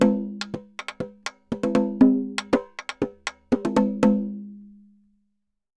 Tambora_Merengue 120_1.wav